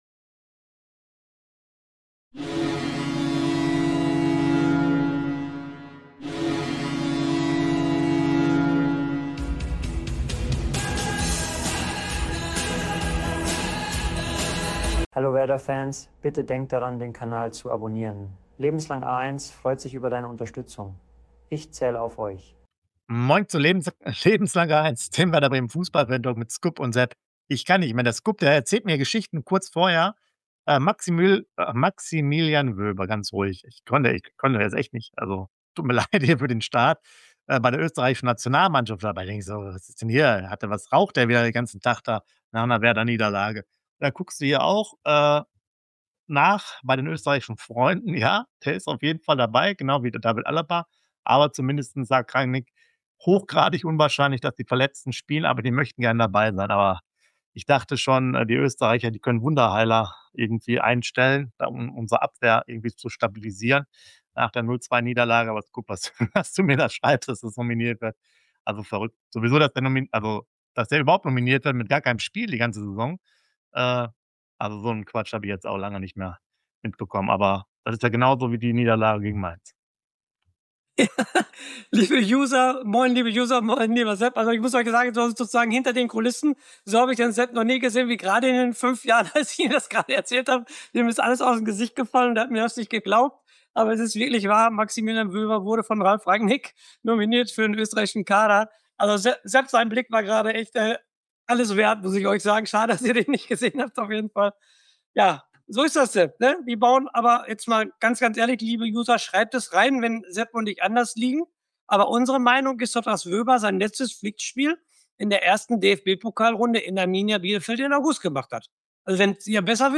Werder Bremen - Fantalk